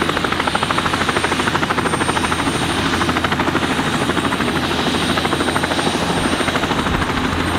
rotor_loop_idle_exterior.wav